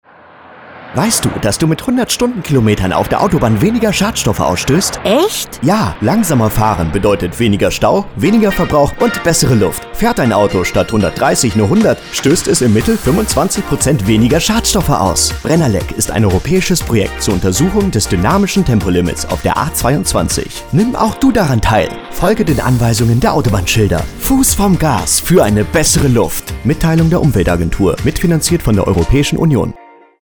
01_Werbung-Radio_Brennerlec-Tempolimit.mp3